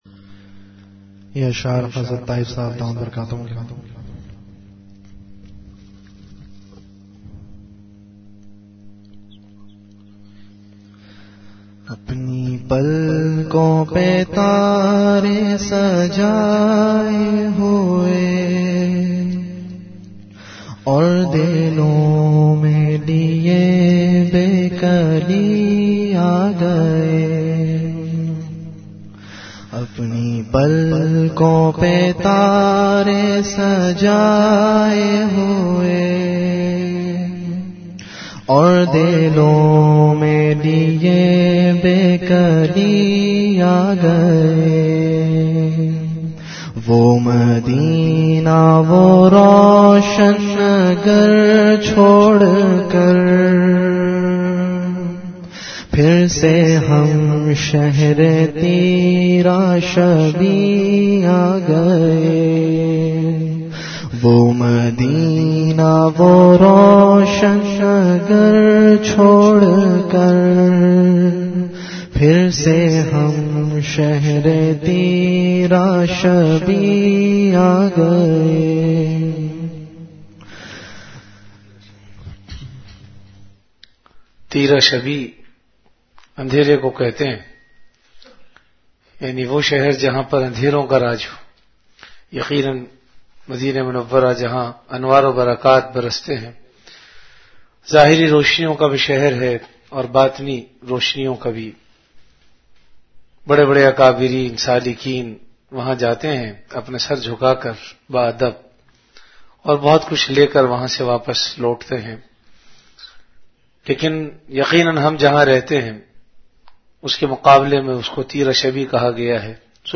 Majlis-e-Zikr
Venue Home Event / Time After Isha Prayer